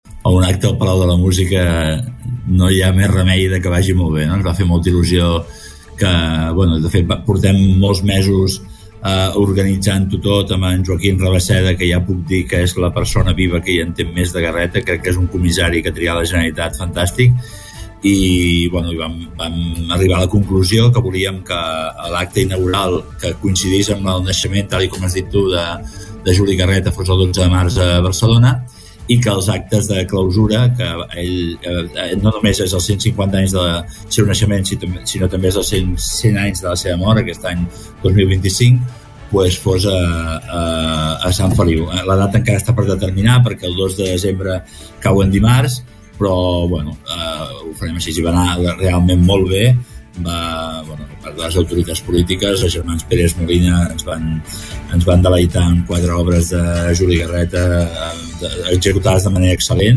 Supermatí - entrevistes
I per parlar d’algunes de les activitats que es faran a Sant Feliu de Guíxols ens ha visitat al Supermatí el regidor de cultura d’aquest municipi, Alexis Cubó.